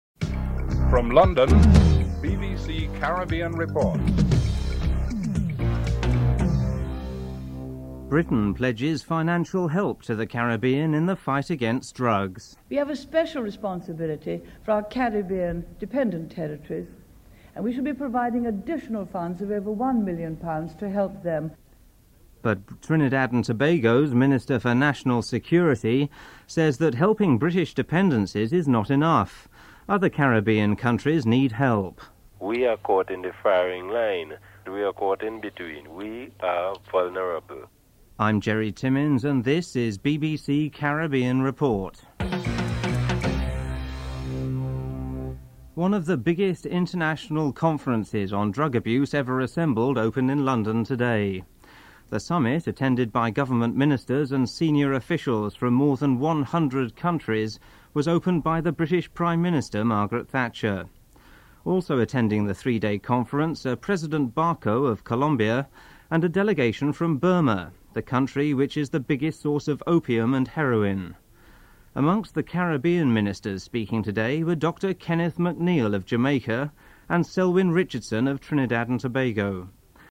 1. Headlines (00:00-00:45)
3. Financial News (10:31-11:21)
Interview with Henry Ford, leader of the Barbados Labour Party (11:22-14:38)